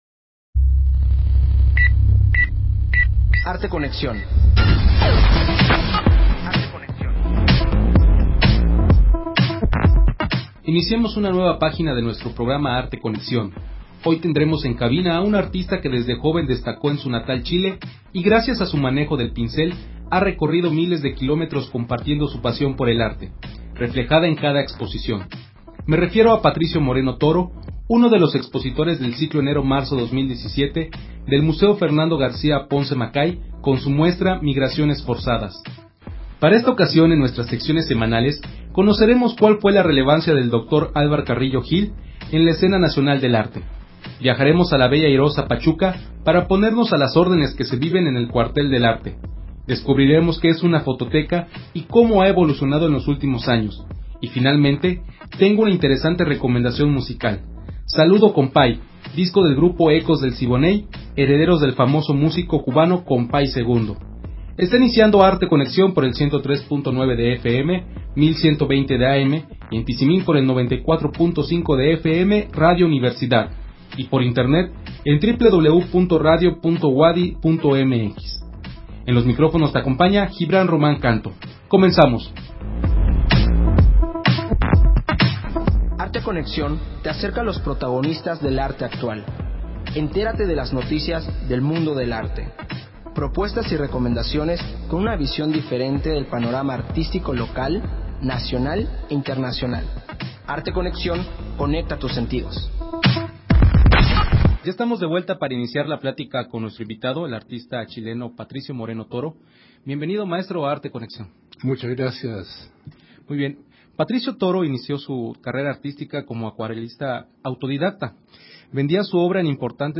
Emisión de Arte Conexión transmitida el 9 de febrero del 2017.